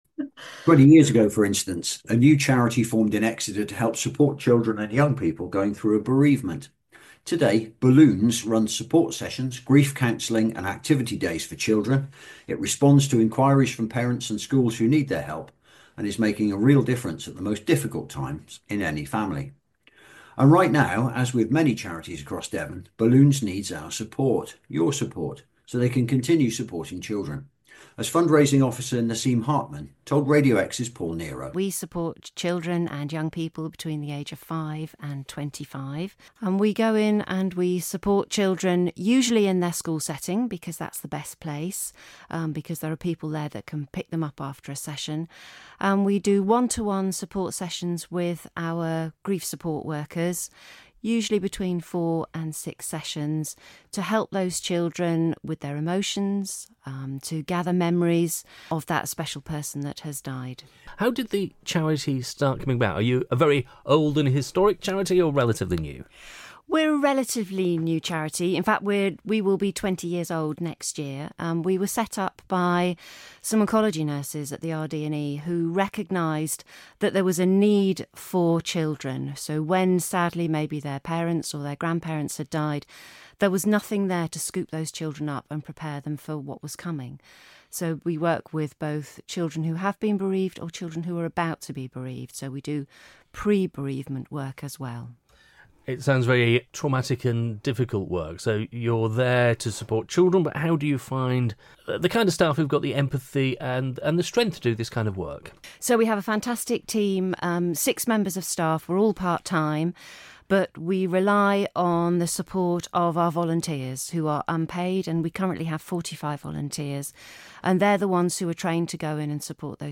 Listen to our Radio Exe interview (7 minutes)